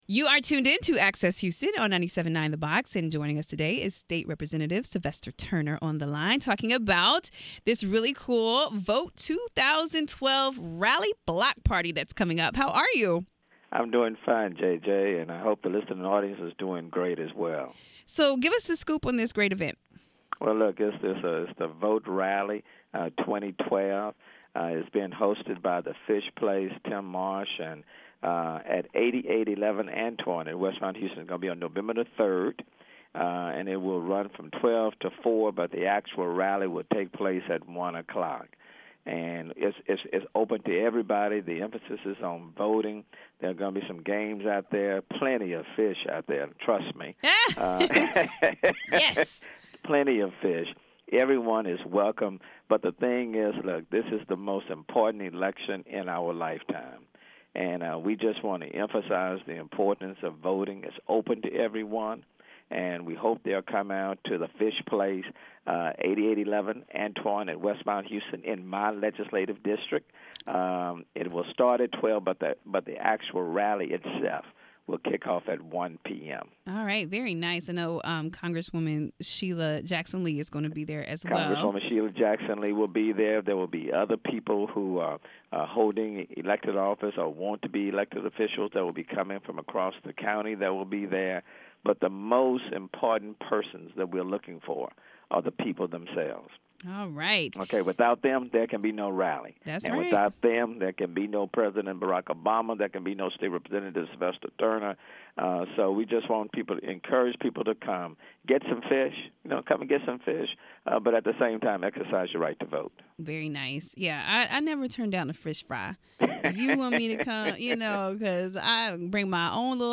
I had a chance to speak with State Representative Sylvester Turner about the VOTE 2012 Rally and Block Party this Saturday!
access-houston-sylvester-turner.mp3